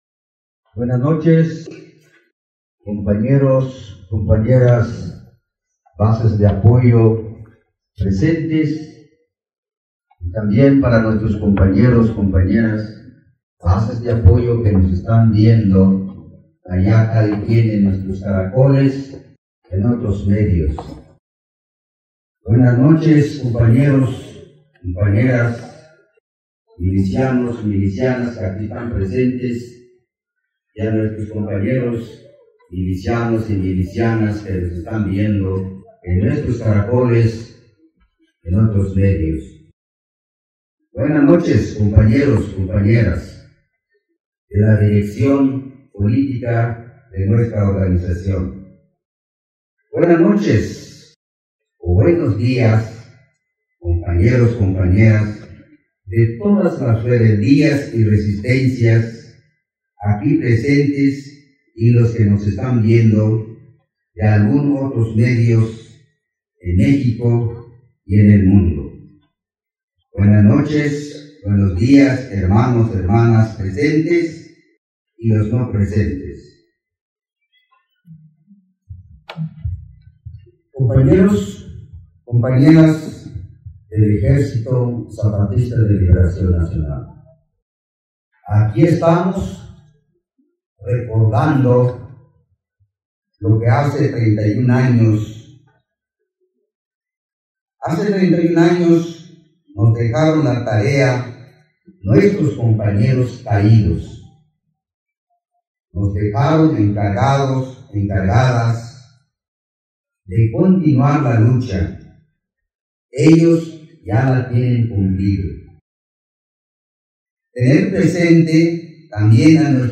Ecos de la Primera Sesión de los Encuentros de Resistencia y Rebeldía. Diciembre 2024 y 1-2 de enero 2025.
moi_discurso_ok.mp3